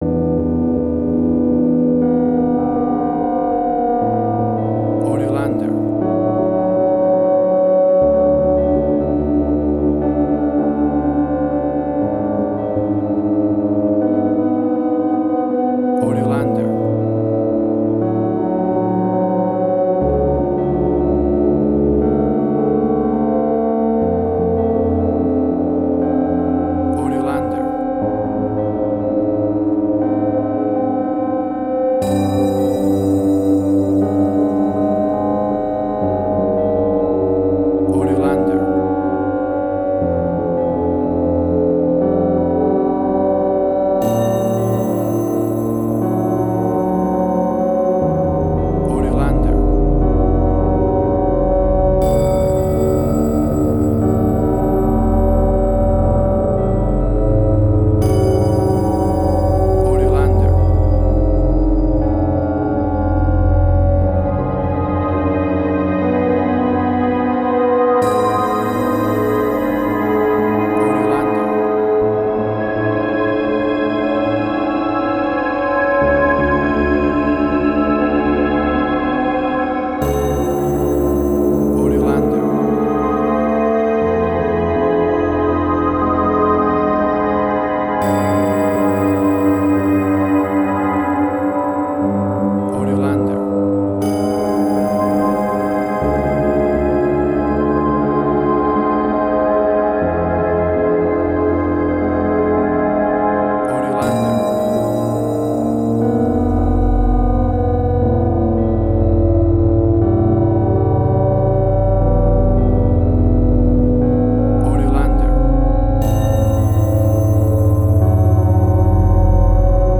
Ambient Strange&Weird.
emotional music